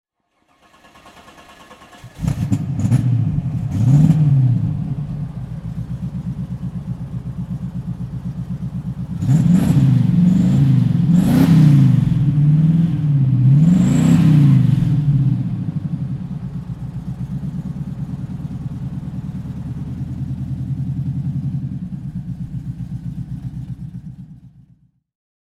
This much can be revealed: The soundscape alone before we set off was impressive...
When we start the engine, we hear the lion roaring out of the exhaust, making the hairs on the back of the driver's neck stand up and the mothers fetch their children from the street.